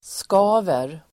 Uttal: [sk'a:ver]